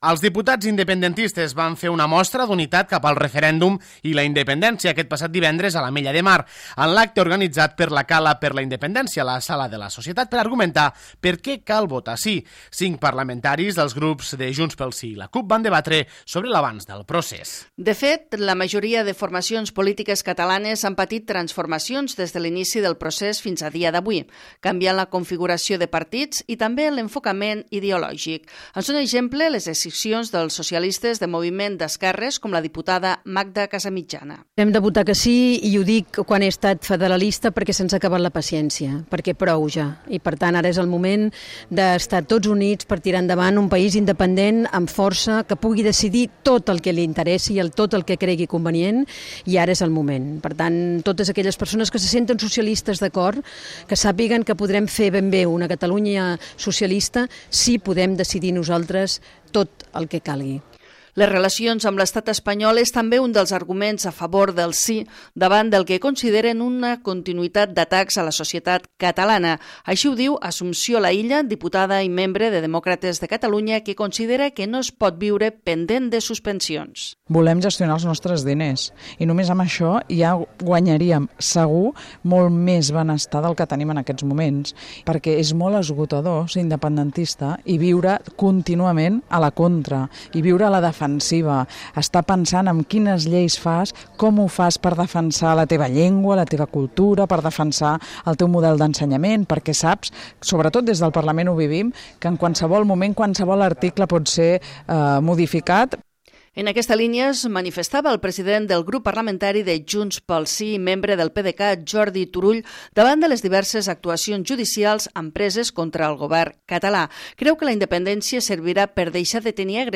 Un centenar de persones van escoltar divendres els arguments dels parlamentaris, que van analitzar l'estat del procés i com s'han creat nous partits independentistes.
Cinc parlamentaris, dels grups de Junts pel Sí i la CUP, van debatre sobre l'avanç del procés.